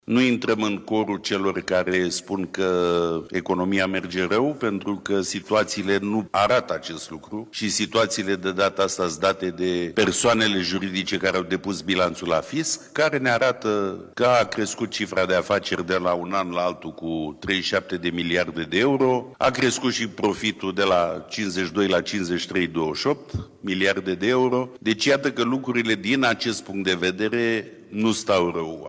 Declarațiile au fost făcute în cadrul unei conferințe organizate de Camera de Comerț și Industrie pe tema reciclării.